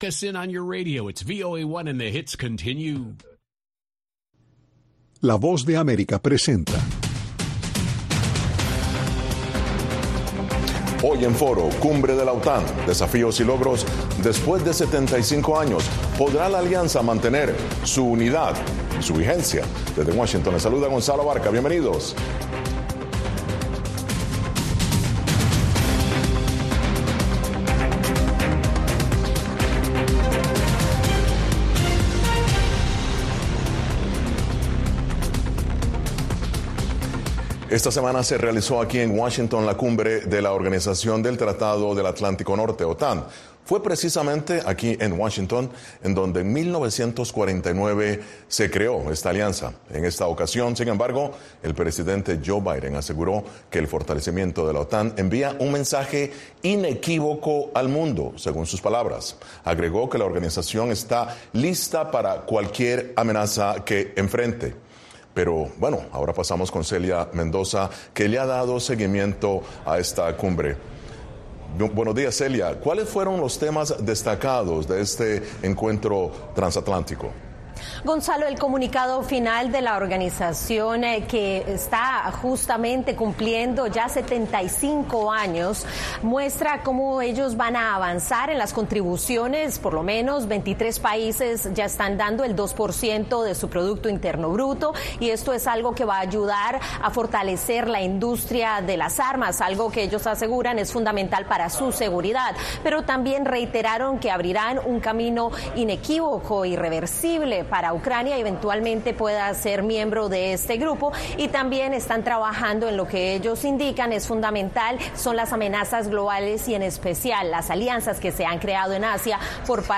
expertos en seguridad nacional, debaten.